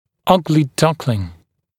[‘ʌglɪ ‘dʌklɪŋ][‘агли ‘даклин]гадкий утёнок